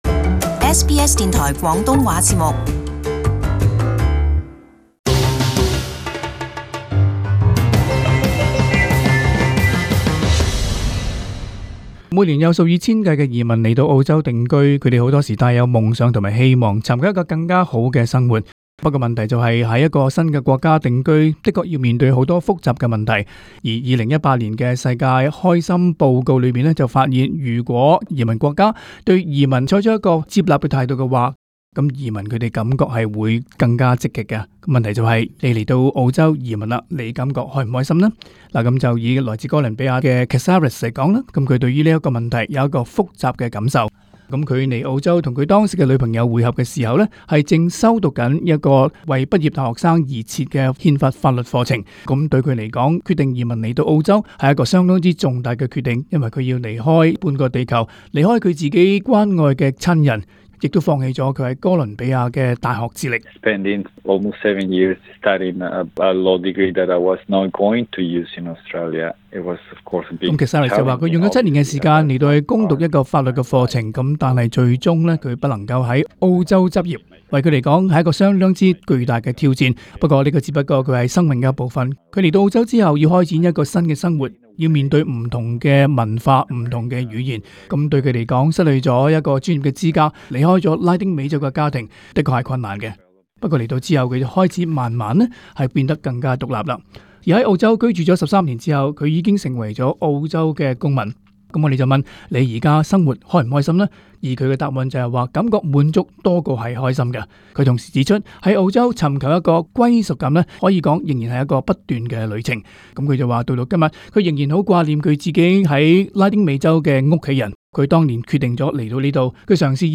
【时事报导】移民梦碎、心理健康何价